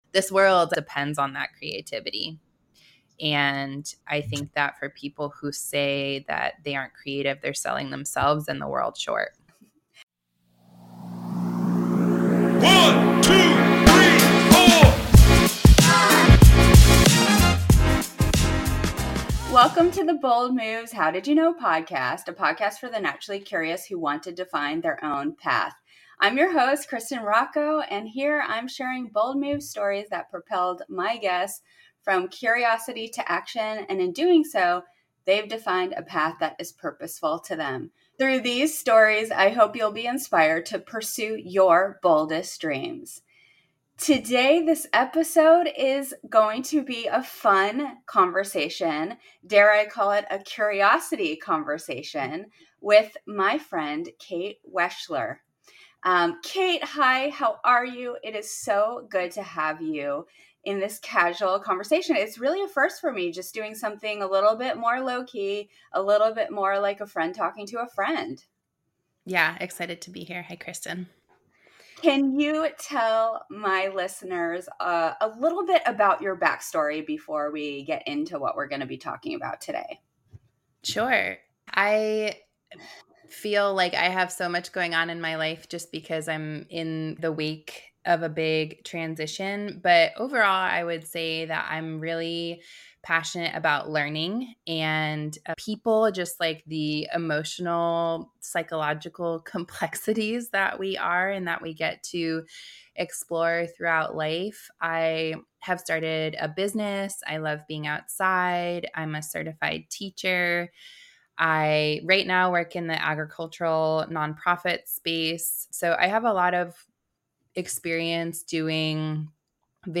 Join us for a meaningful discussion that we hope ignites the creativity within you!